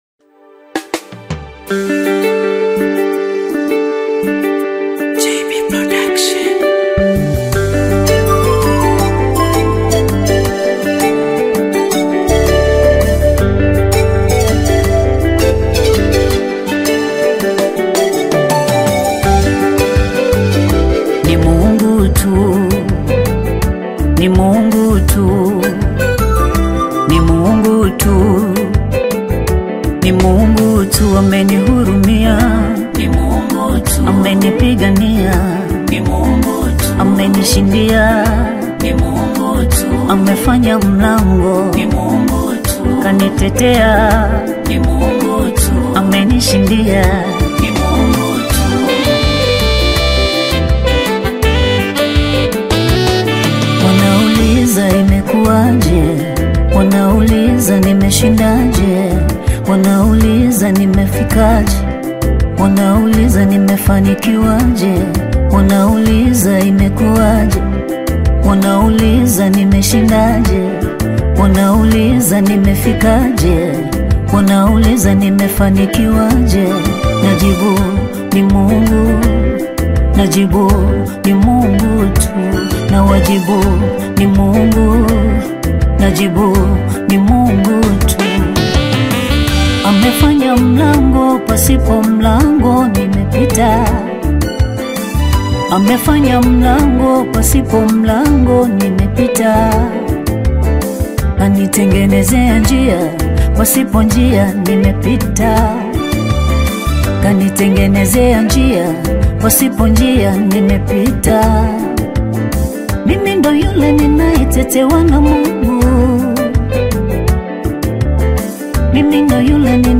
Gospel music track
Tanzanian Gospel artist, singer and songwriter